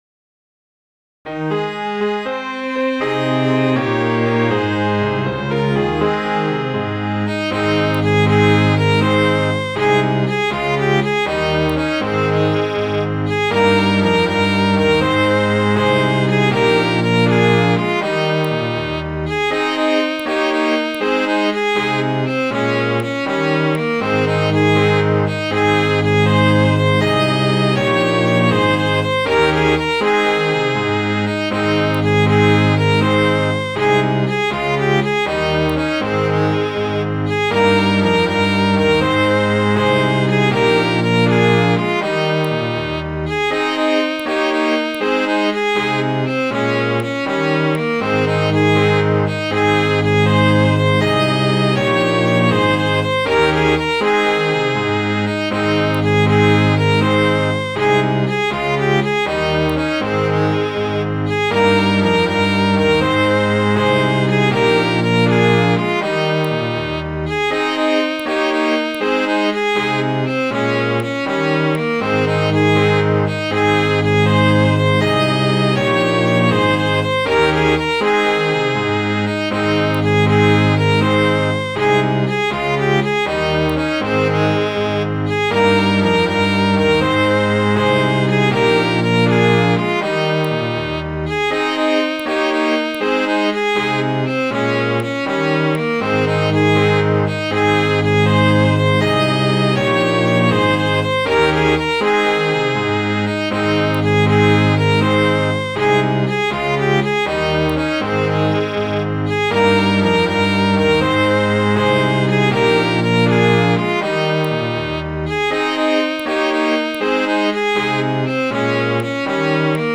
Midi File, Lyrics and Information to Travel the Country Round